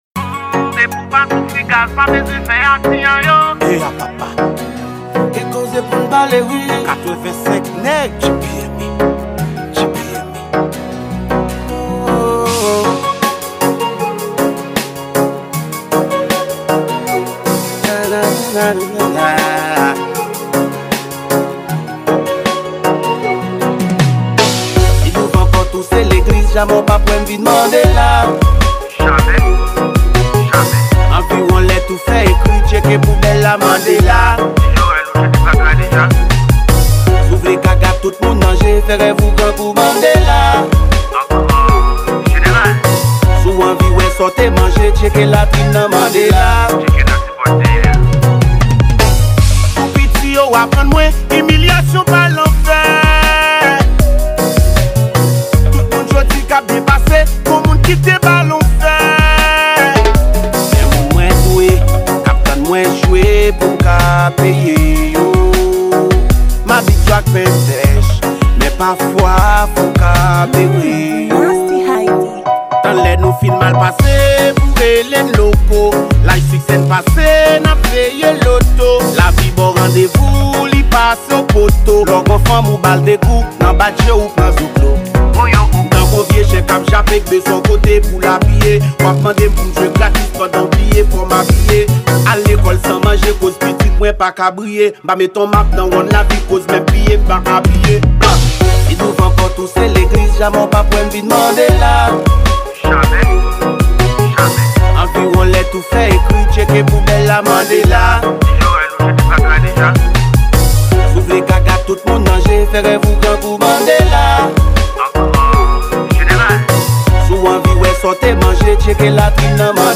Genre: Reggea.